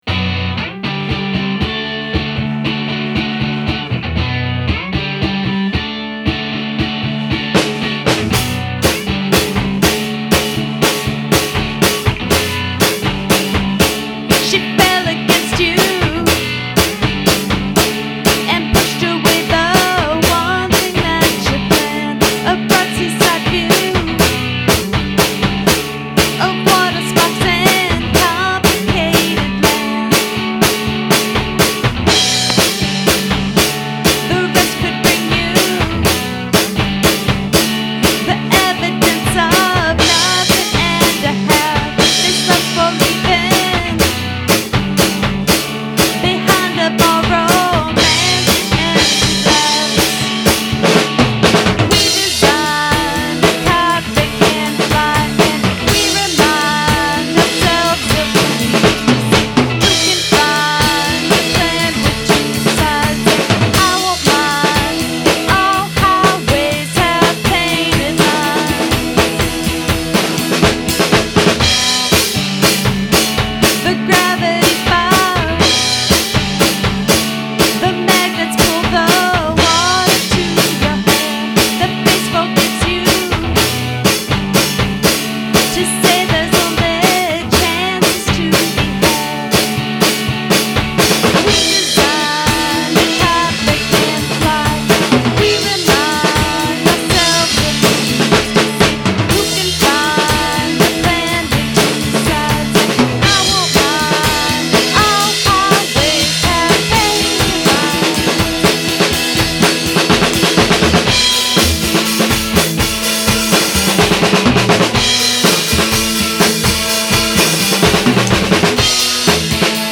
3-2-2010 Recording Session